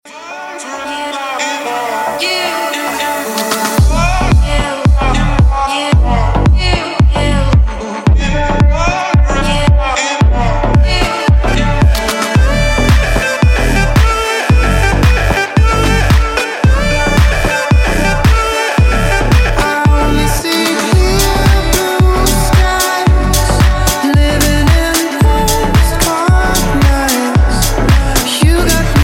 • Качество: 128, Stereo
красивый мужской голос
мелодичные
Саксофон
Стиль: deep house